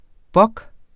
Udtale [ ˈbʌg ]